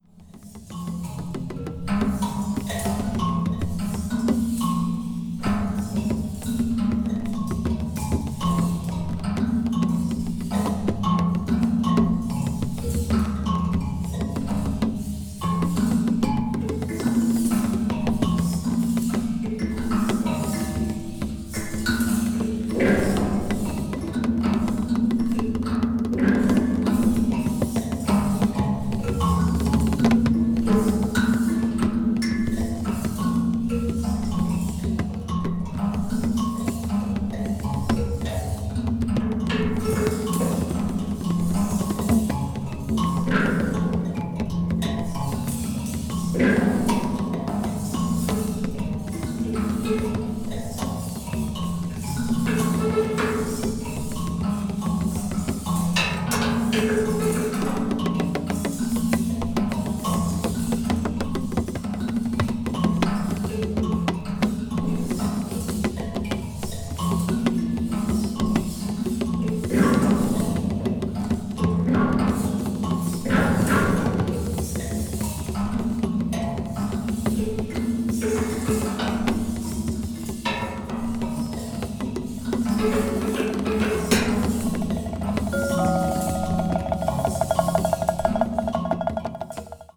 media : EX/EX(some slightly noises.)